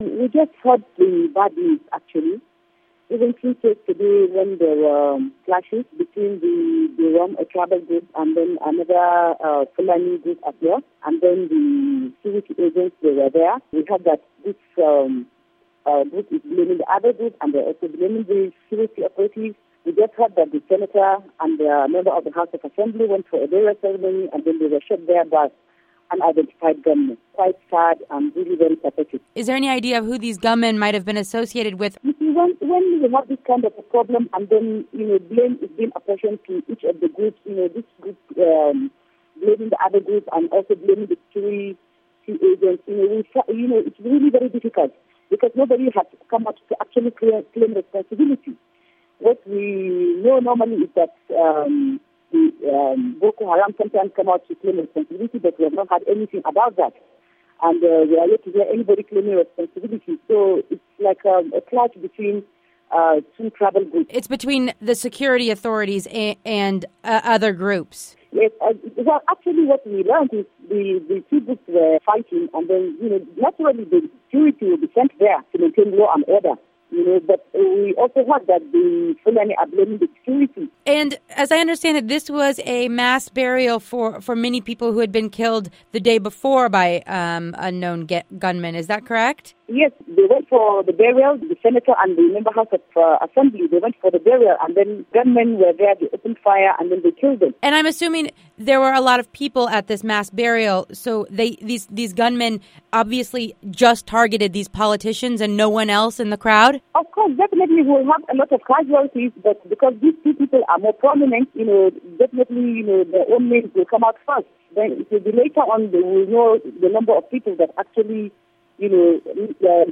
Northern Nigeria politician Hafsat Baba talks